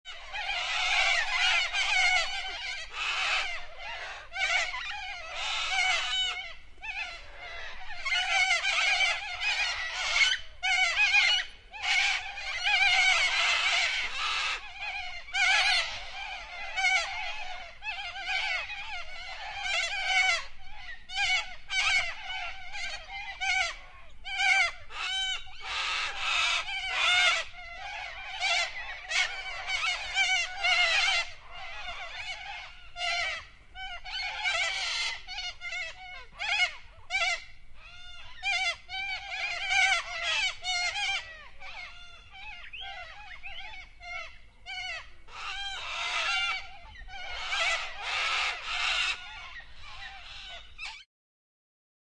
Cacatúa ninfa (Nymphicus hollandicus)